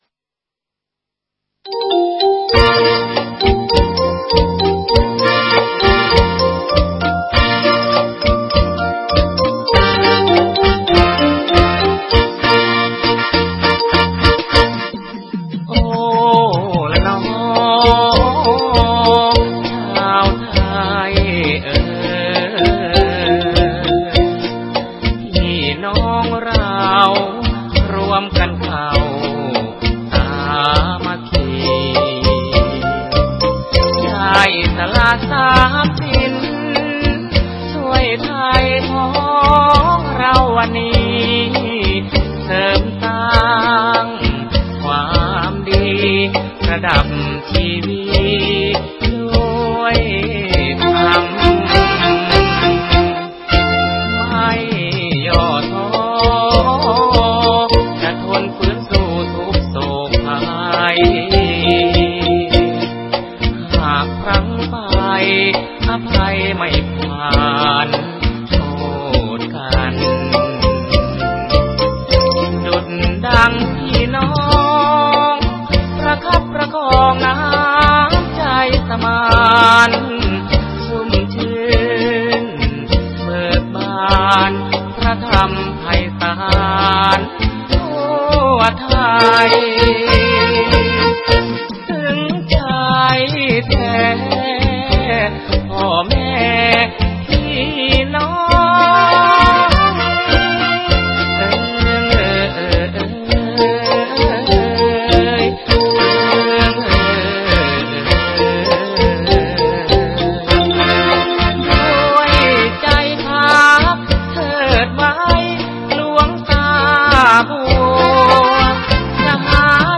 บทเพลงลูกทุ่ง